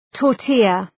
Προφορά
{tɔ:r’ti:lə}